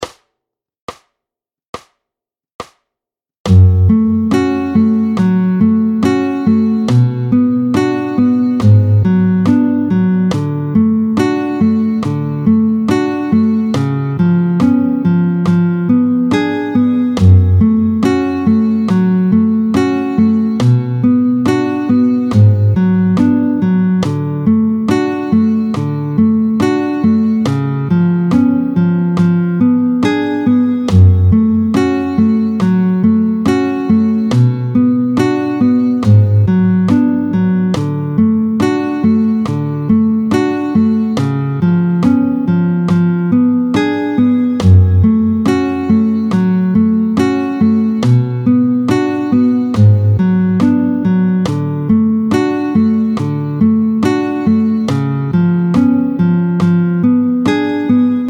21-08 Song for Bernard suite, tempo 70